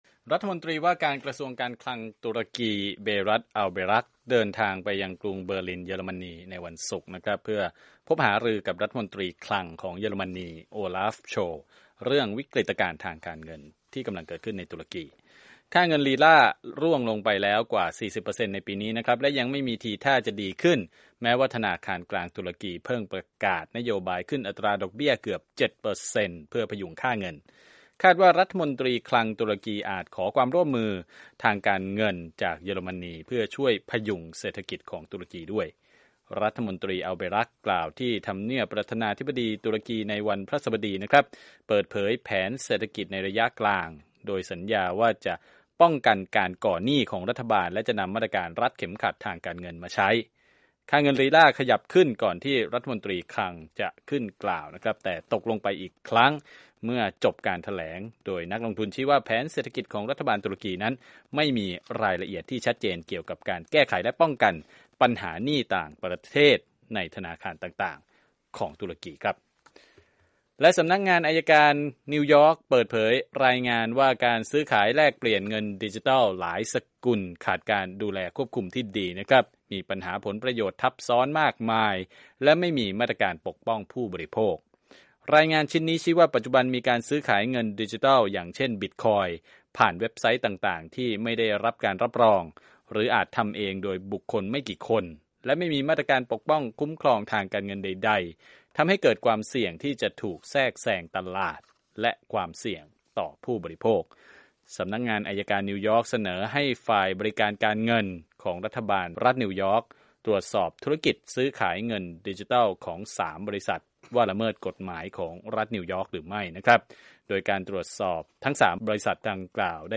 ข่าวธุรกิจ 9/20/2018